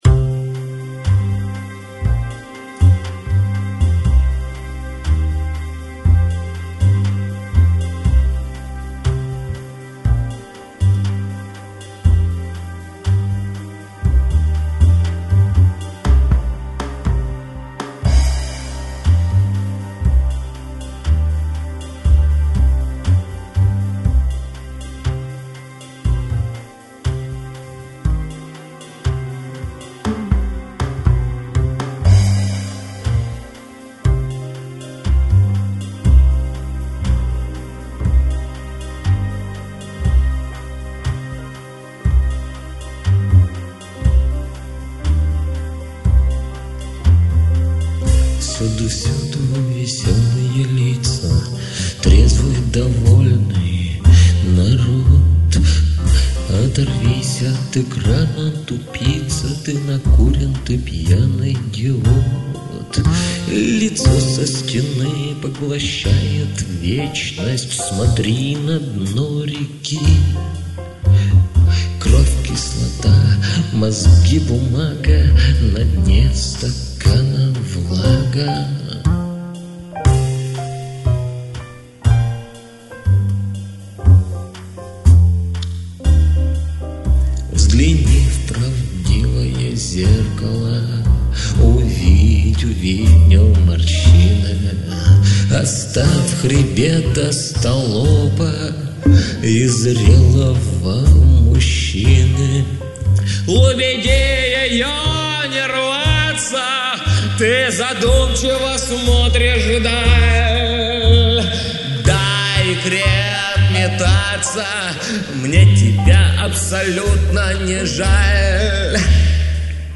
пение
клавишные
запись осуществлена в студии